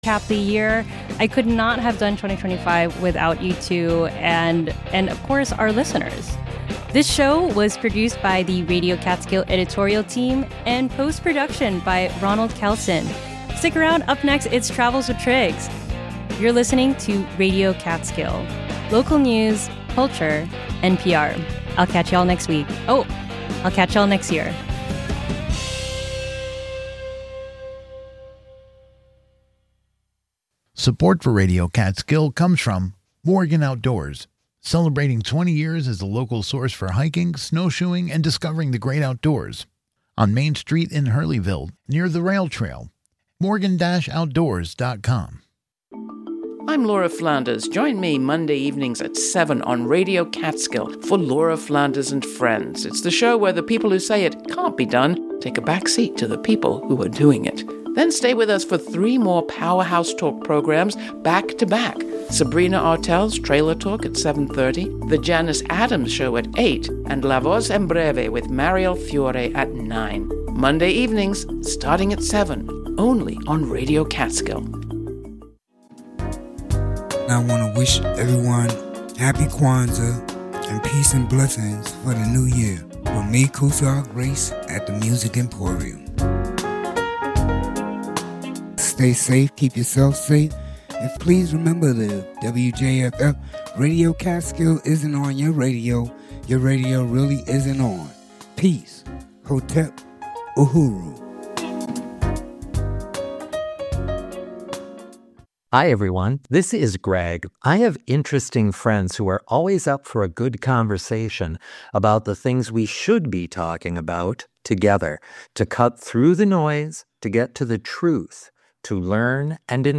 There are lot of amazingly talented and interesting people right here in the Catskills. Catskill Character is a personality profile show